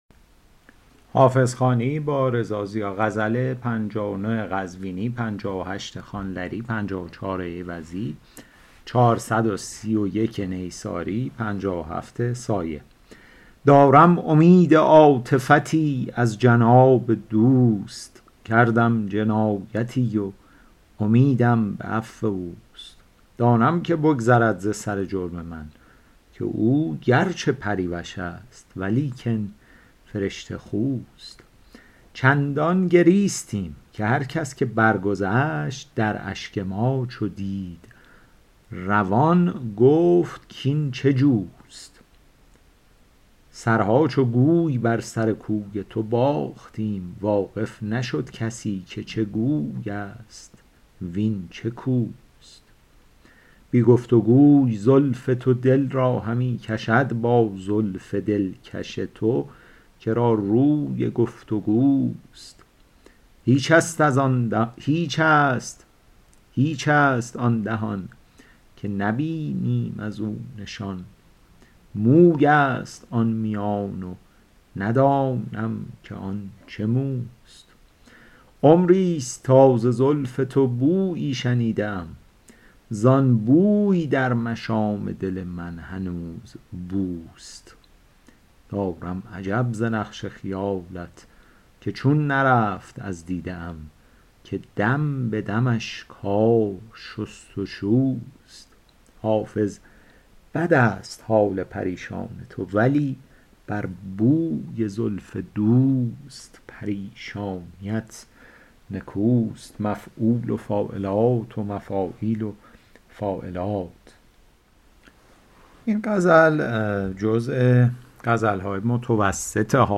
شرح صوتی غزل شمارهٔ ۵۹